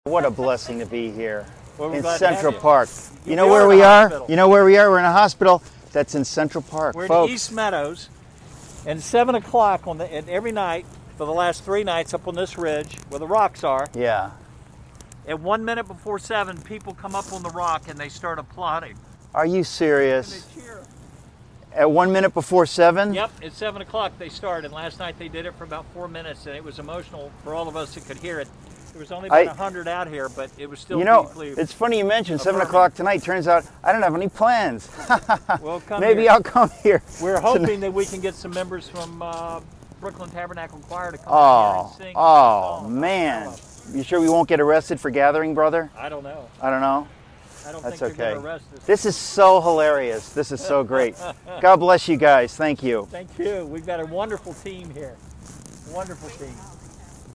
On this ridge, people applaud.